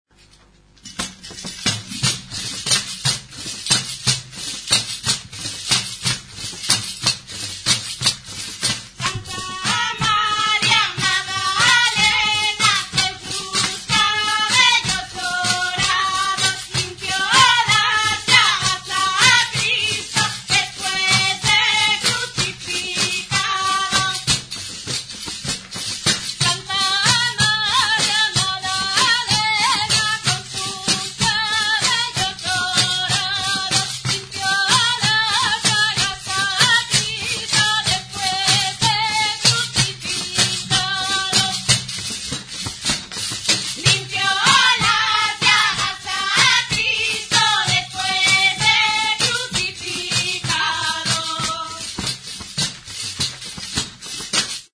Membranophones -> Beaten -> Tambourines
EUROPE -> ASTURIAS
PANDERU; PANDEIRU
Metalezko 14 txinda pare ditu bi lerrotan jarririk.